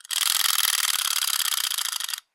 Звуки заводных игрушек
Звук трещотки заводной игрушки